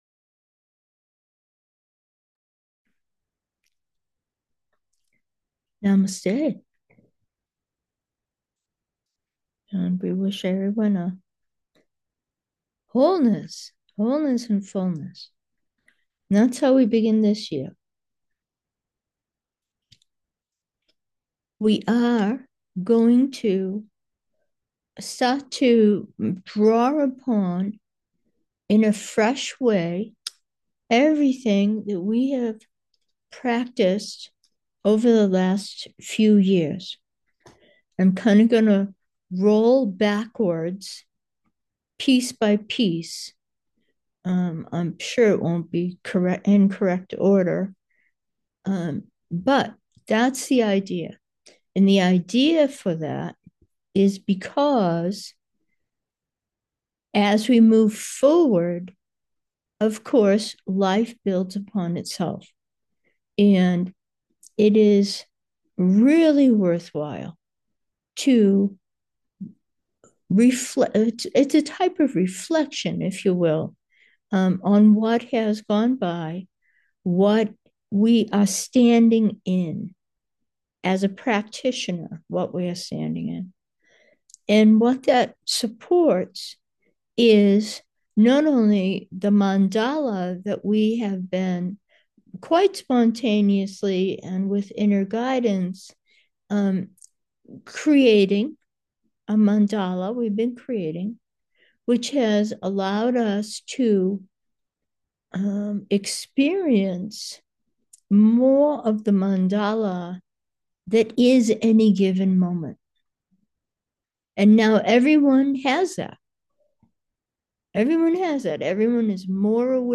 Meditation: newness, presence 1